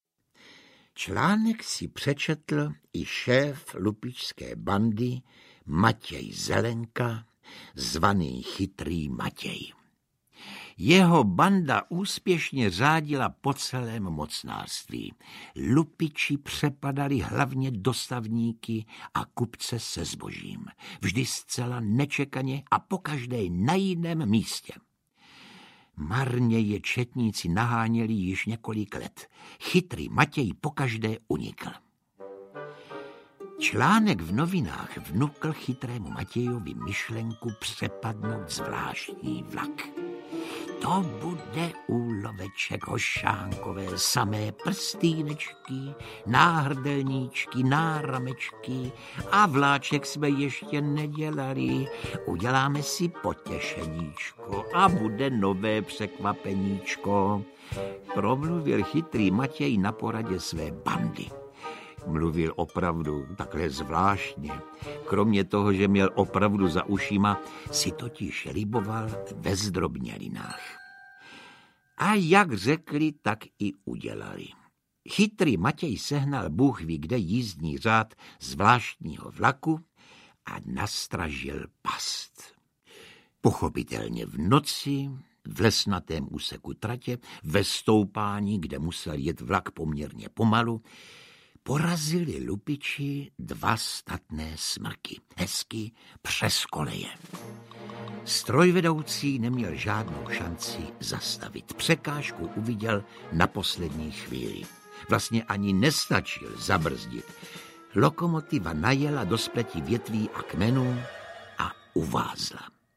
Ukázka z knihy
Role Vypravěče se ujal výpravčí na slovo vzatý – pan Josef Somr.
• InterpretJosef Somr